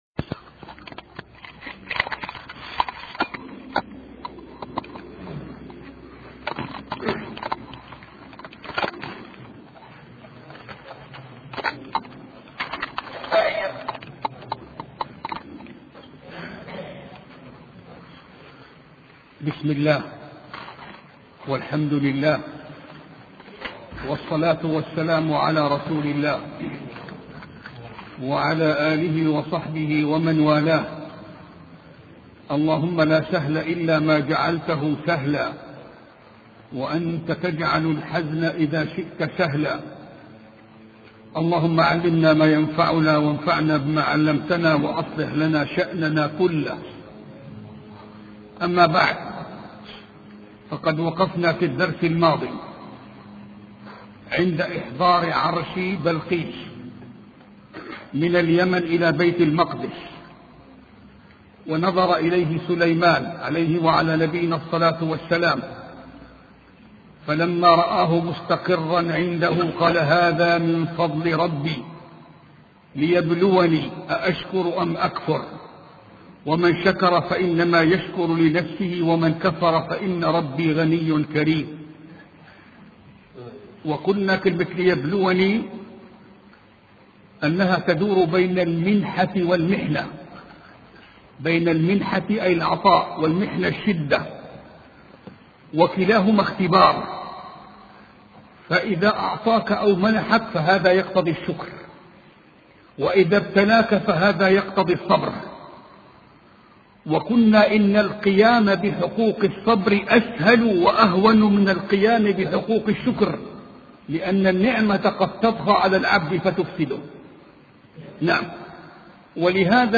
سلسلة محاضرات في قصة سليمان علية السلام